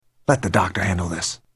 Category: Movies   Right: Personal
Tags: Planet 51 Planet 51 clips Planet 51 movie Planet 51 sound clips Animation